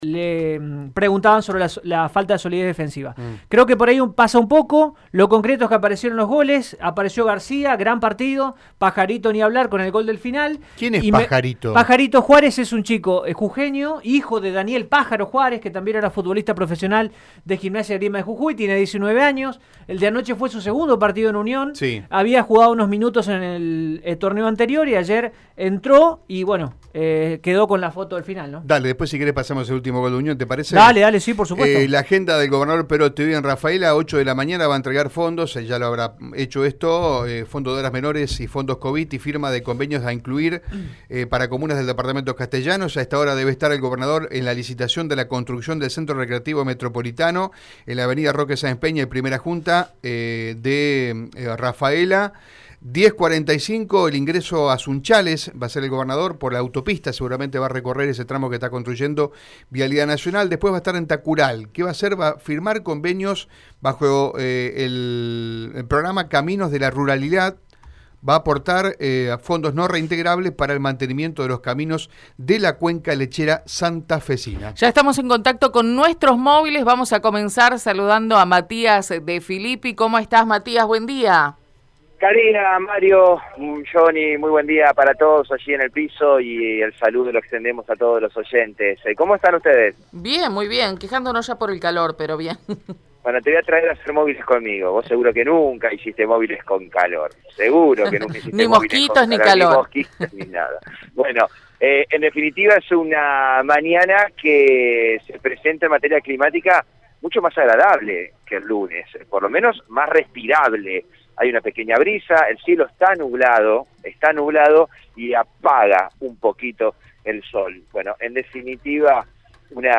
adelantó Ignacio Martínez Kerz, secretario de Prácticas Sociocomunitarias de la provincia de Santa Fe